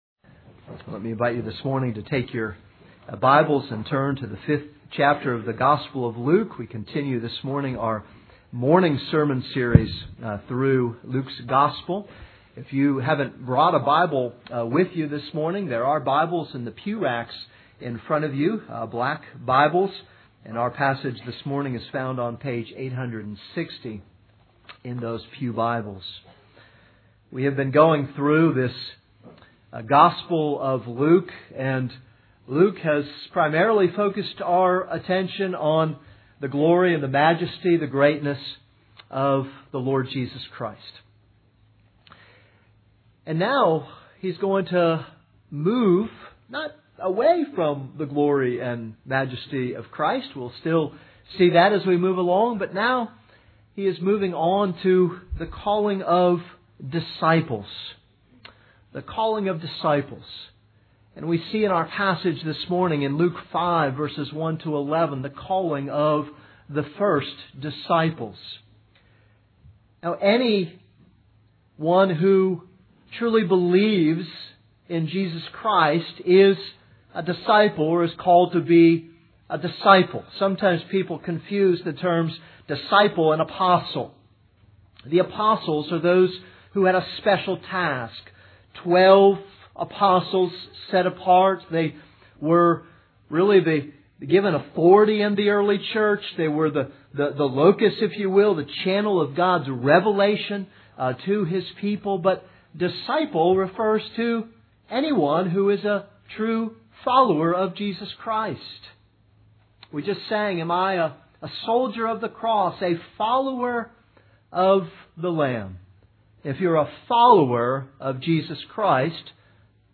This is a sermon on Luke 5:1-11.